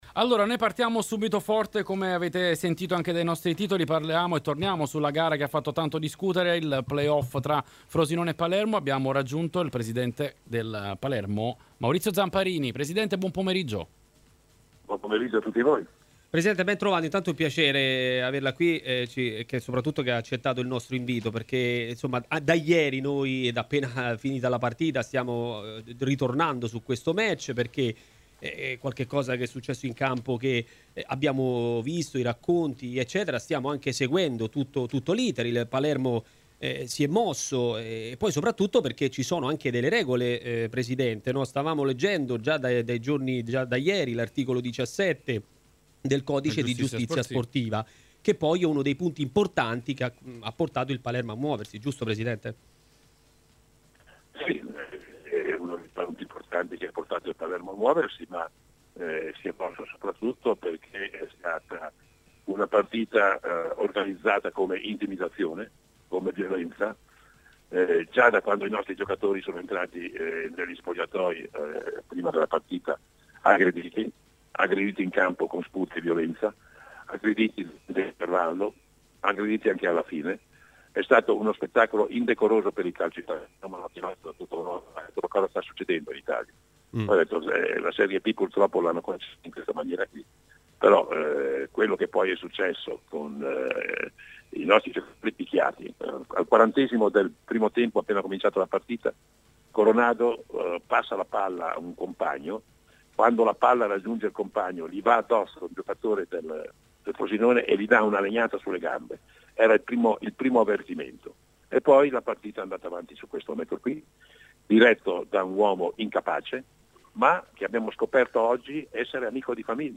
Maurizio Zamparini (presidente Palermo)intervistato